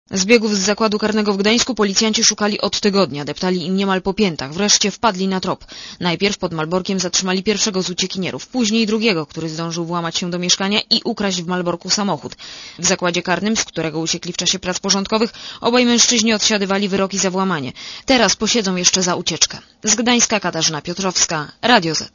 Korespondencja z Malborka Oceń jakość naszego artykułu: Twoja opinia pozwala nam tworzyć lepsze treści.